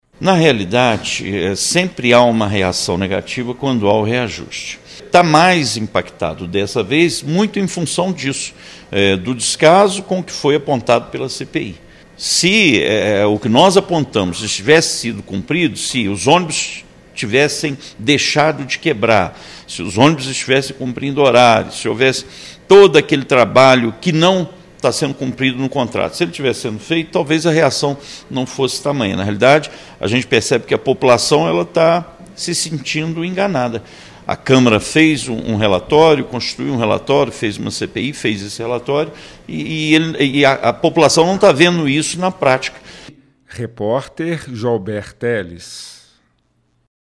vereador José Márcio Garotinho